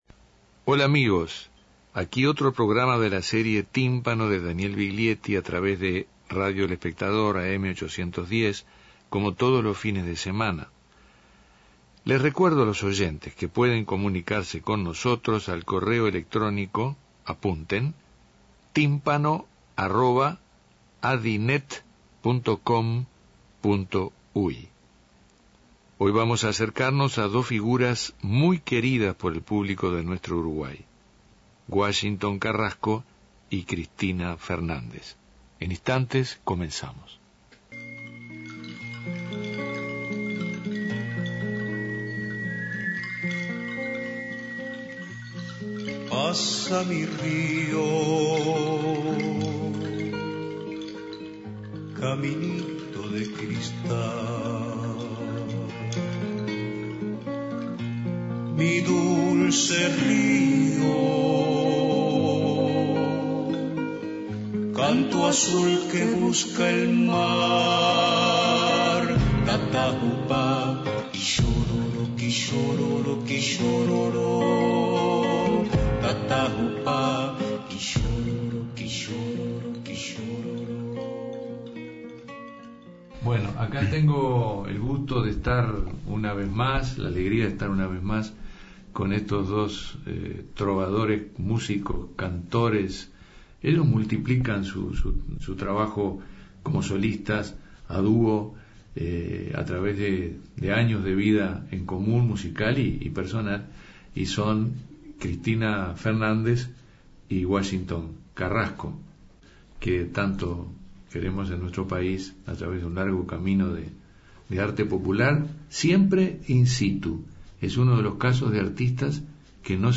Escuche el programa de Tímpano con el dúo de música popular uruguaya compuesto por Washington Carrasco y Cristina Fernández.